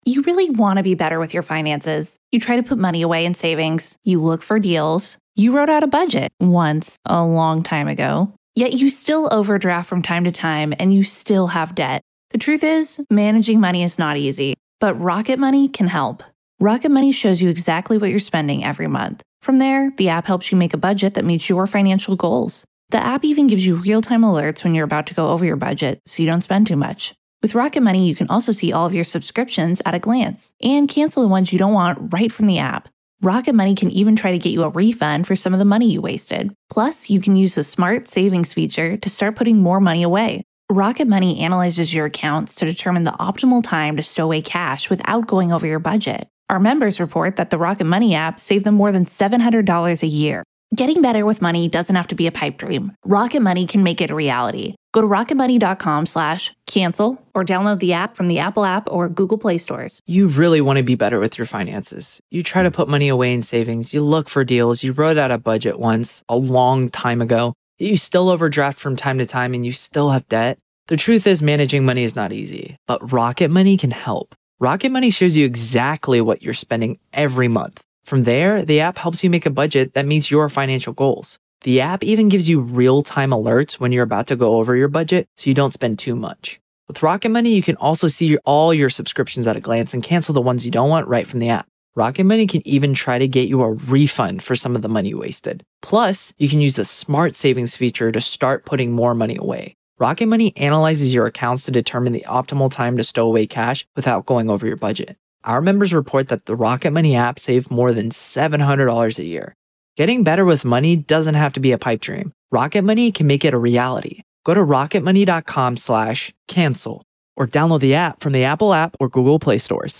The latest Spanish news headlines in English: September 1st 2025